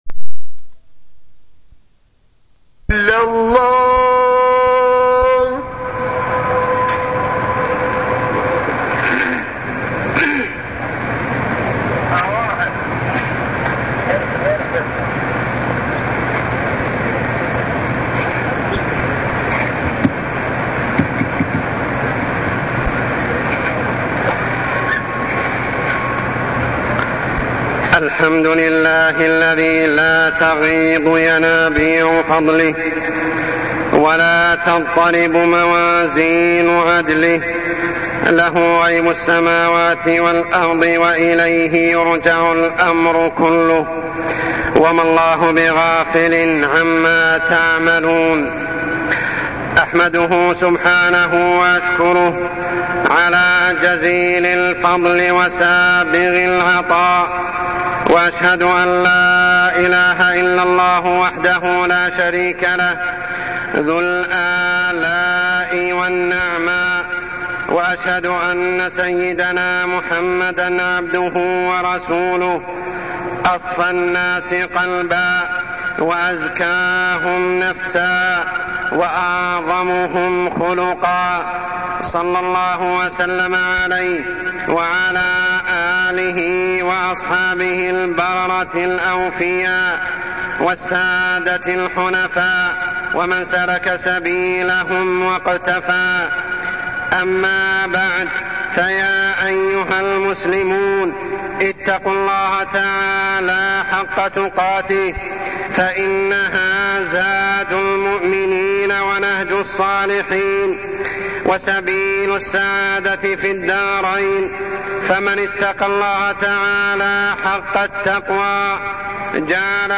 تاريخ النشر ٢١ جمادى الآخرة ١٤٢٠ هـ المكان: المسجد الحرام الشيخ: عمر السبيل عمر السبيل داء الحسد